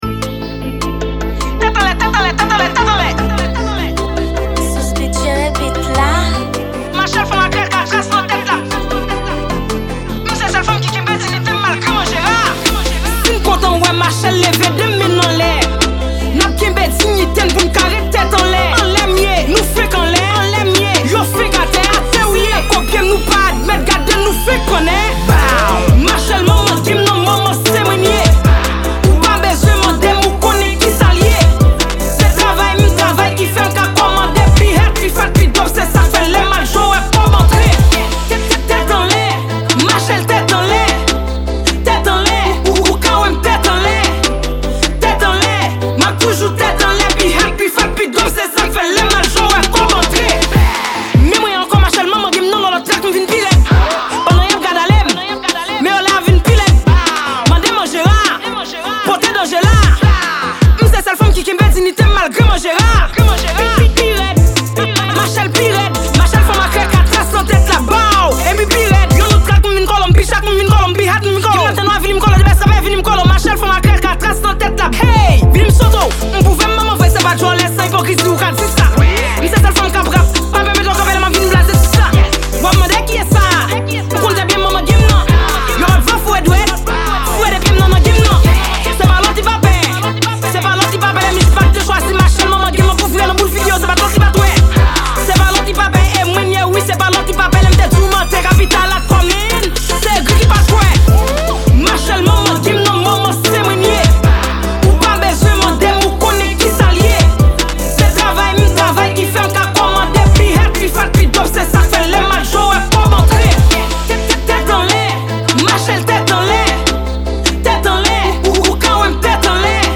Genre:Trap